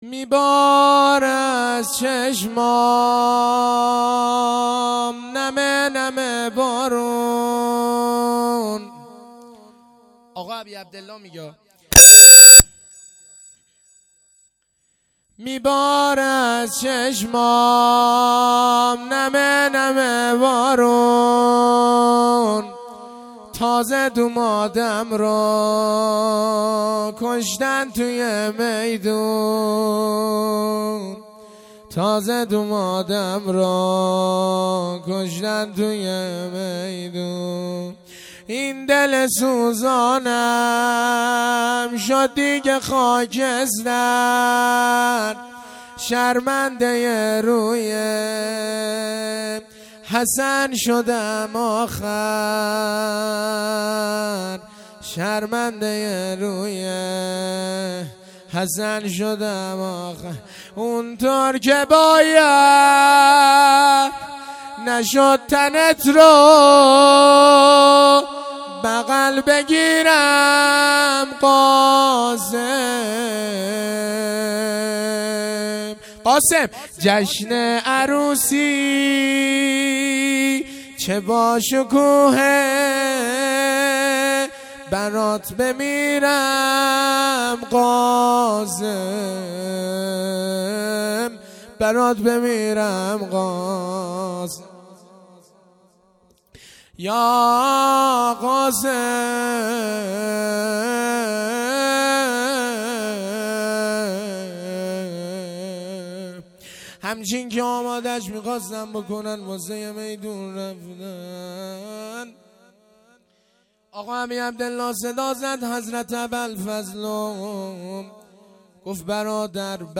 روضه
شب ششم محرم الحرام ۱۴۴۳